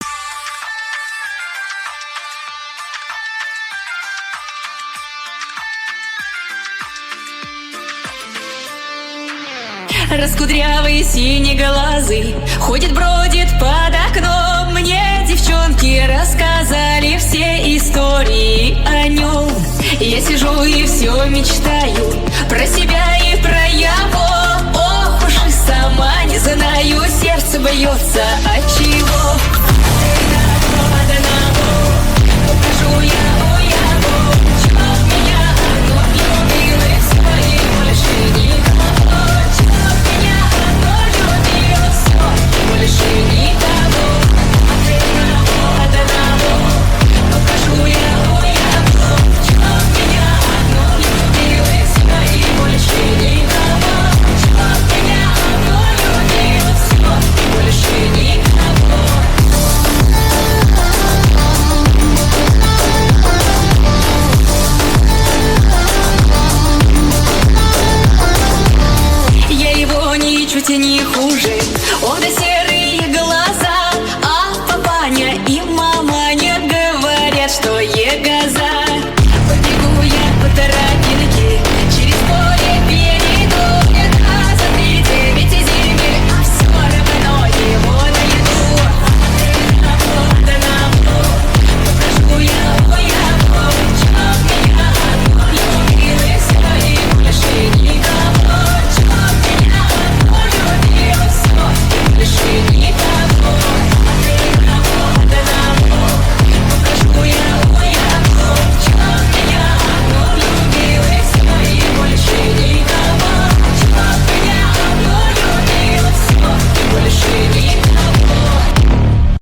Ультра Фанк Slowed Reverb TikTok Remix